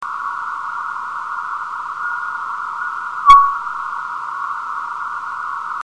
suono prodotto da una meteora ipodensa